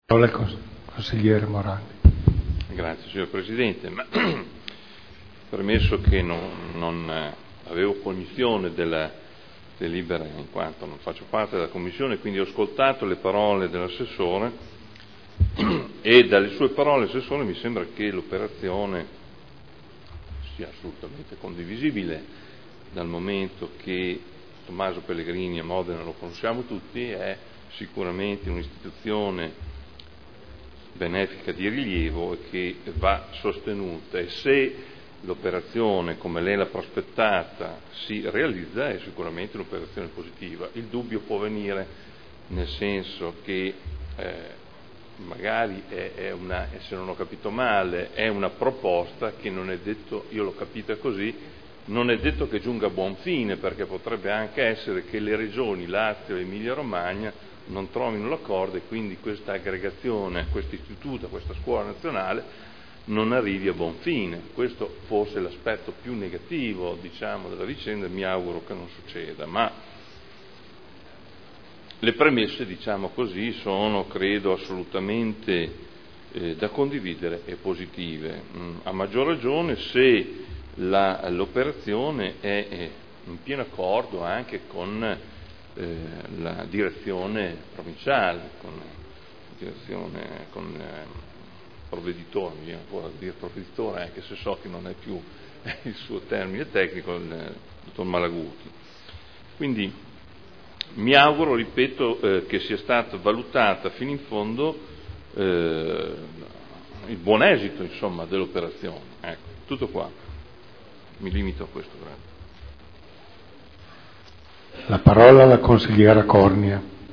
Seduta del 13/12/2010 Deliberazione: Aggregazione sezione audiolesi scuola secondaria di 1° grado Lanfranco